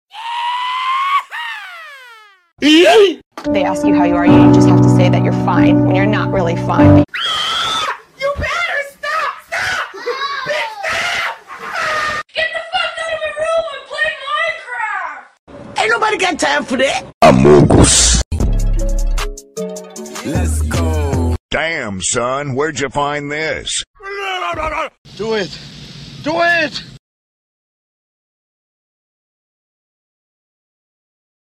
Boost Your Edits with These sound effects free download
This FREE sound effects pack has everything you need — from cinematic hits and risers to funny pops and slick transitions.